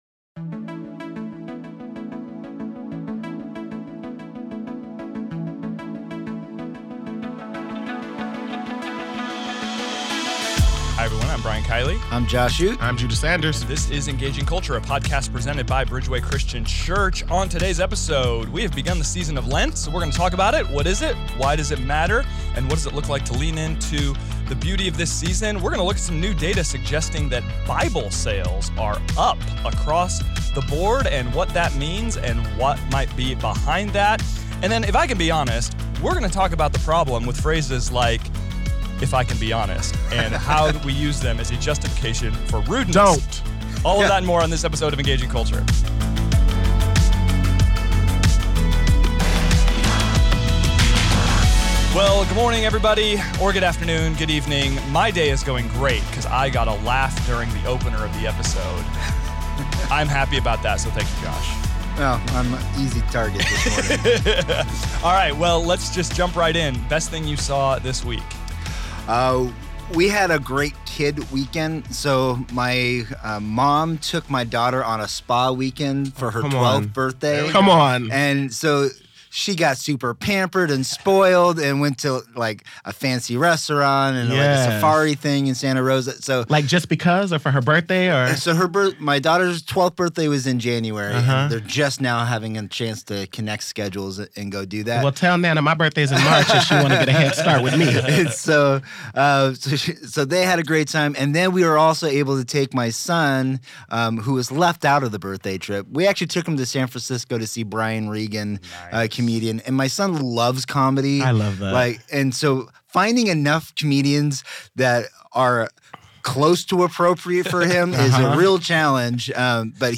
begin this episode with a discussion of the purpose of the lenten season and the purpose of the spiritual discipline of fasting. Then, they look at recent increases in Bible sales and reflect on what that might mean for society at large. Next, they talk about the harm that comes from phrases like "Can I be honest?" and "I'm just sayin'" and how we can get better at communicating hard information in a kind and helpful manner. They end with another round of their game Who Said It?